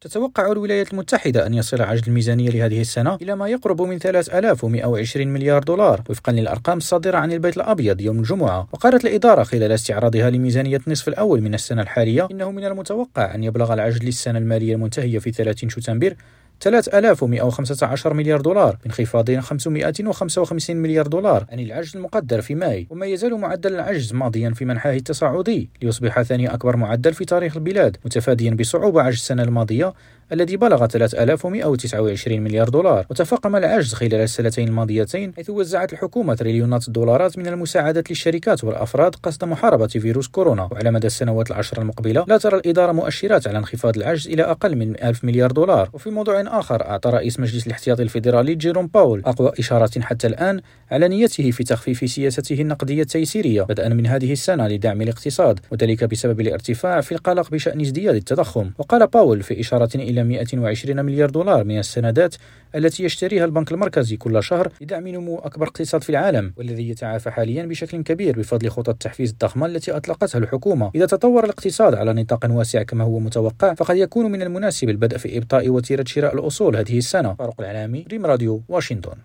نشرة الأخبار الاقتصادية لأمريكا الشمالية